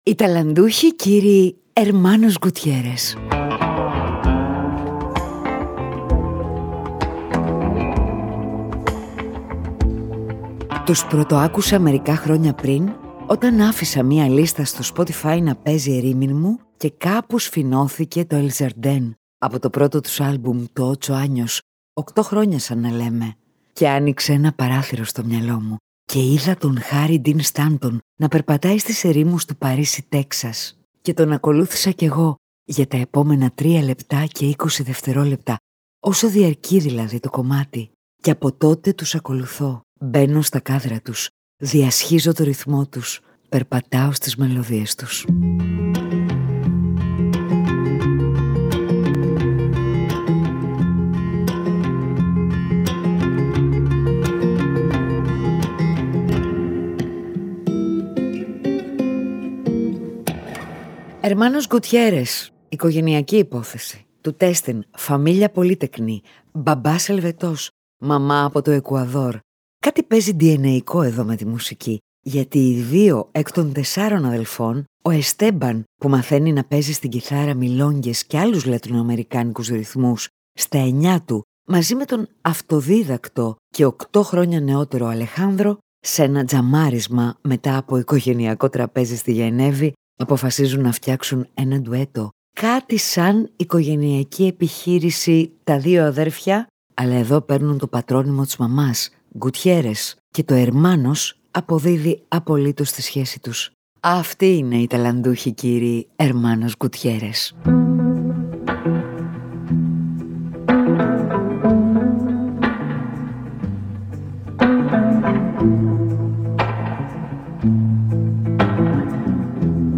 Αλλιώς οι αδελφοί Gutiérrez… οικογενειακή υπόθεση για μια κιθάρα και μια χαβάγια και μουσικές ορχηστρικές που περιγράφουν τοπία, μουσικές σκονισμένες για ανοιχτούς ορίζοντες, μουσικές για να ταξιδεύεις και να ησυχάζει το μέσα σου.